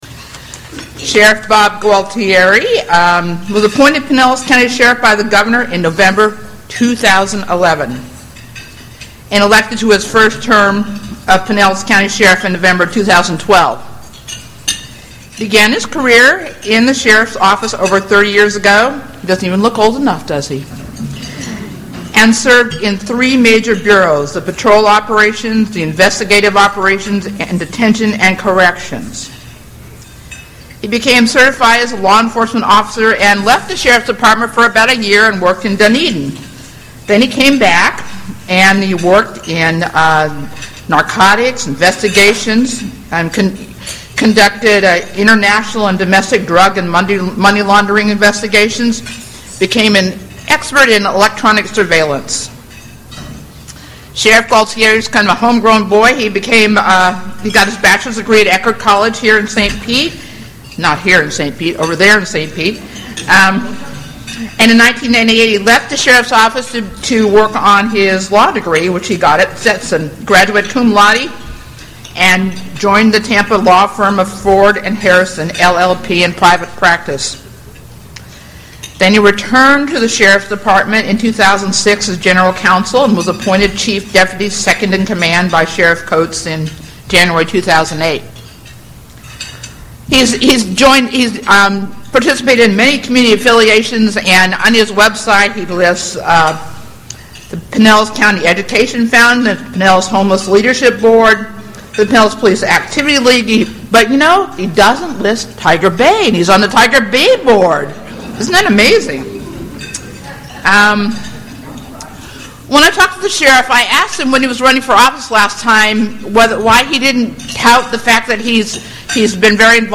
Suncoast Tiger Bay Club 10/23/13 rebroadcast